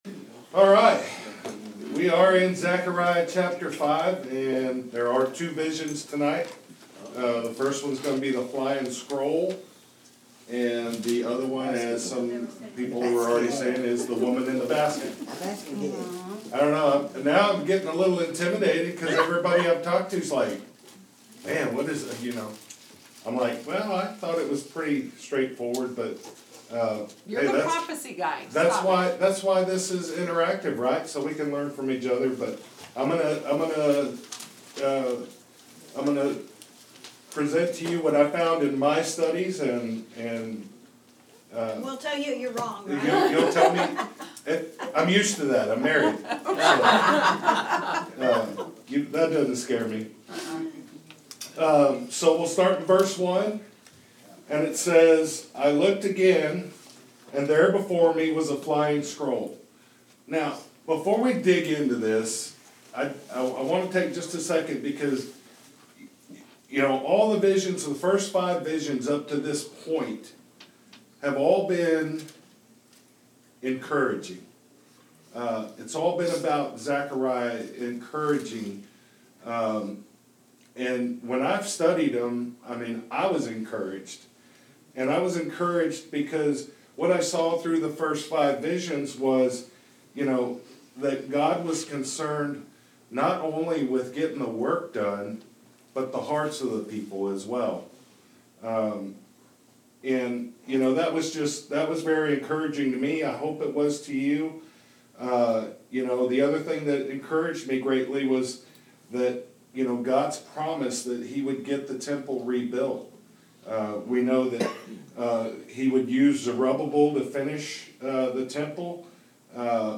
Interactive Bible Study